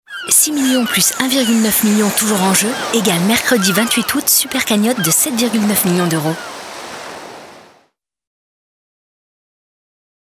Loto (voix conclusion, naturelle)